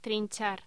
Locución: Trinchar